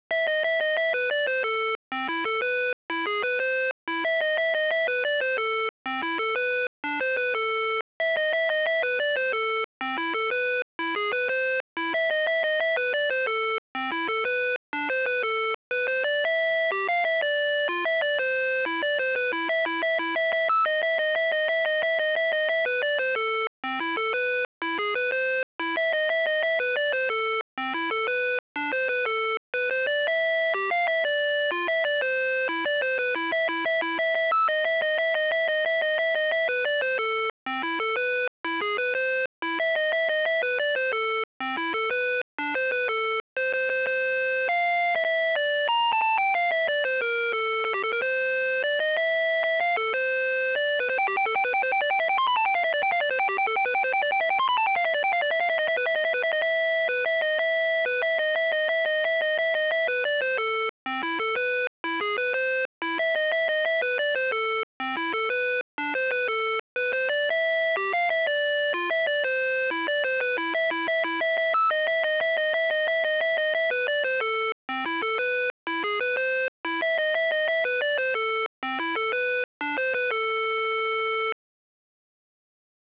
(648KB) Fur_Elise.zip (53KB) Für Elise as generated by FX-502P